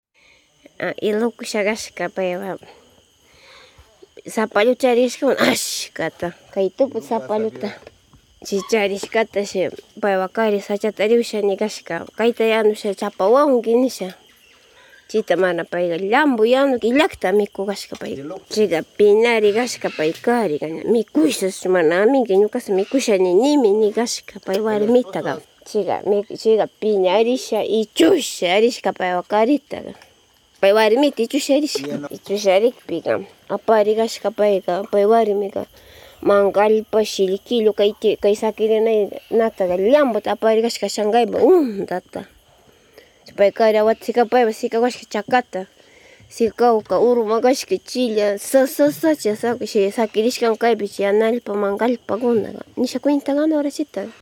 En la narración